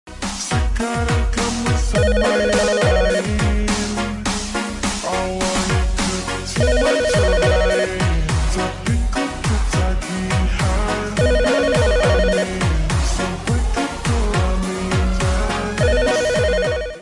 Genre: Nada dering viral TikTok